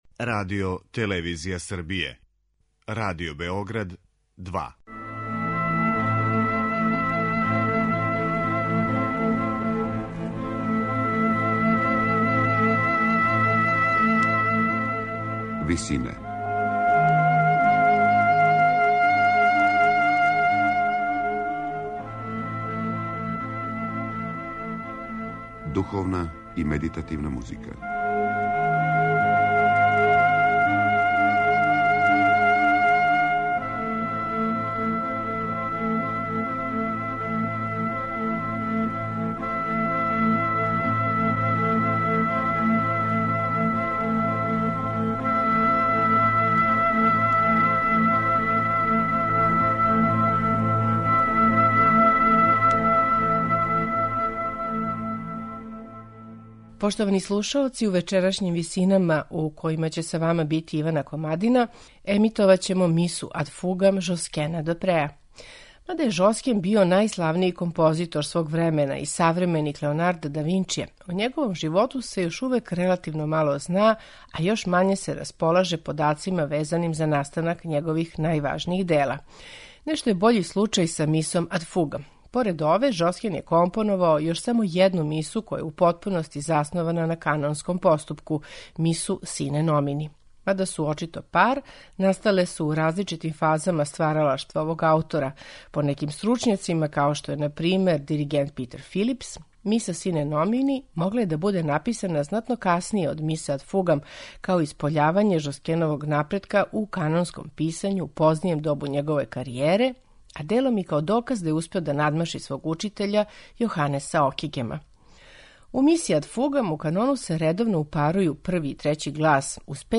У првој од ове две мисе у канону се редовно упарују само први и трећи глас, уз шест деоница са независним садржајем, док се размак између канонских линија мења од става до става. Отуда је канонски поступак овде транспарентан и релативно лак за праћење.